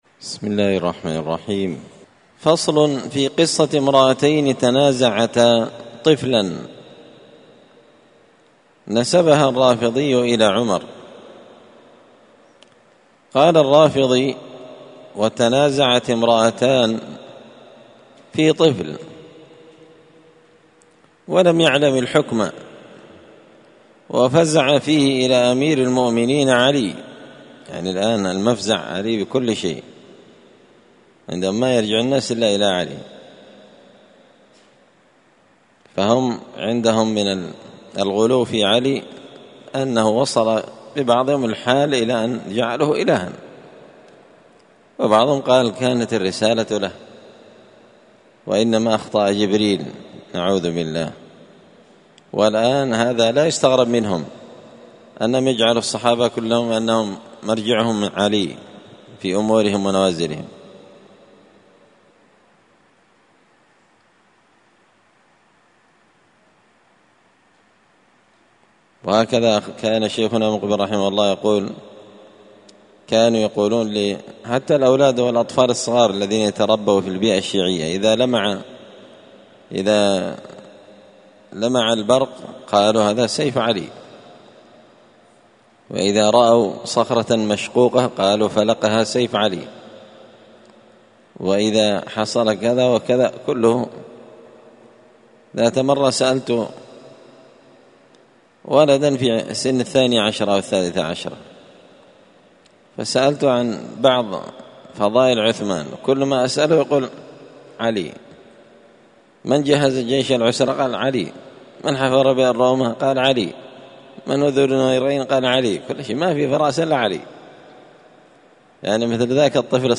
الخميس 18 ذو الحجة 1444 هــــ | الدروس، دروس الردود، مختصر منهاج السنة النبوية لشيخ الإسلام ابن تيمية | شارك بتعليقك | 7 المشاهدات
مسجد الفرقان قشن_المهرة_اليمن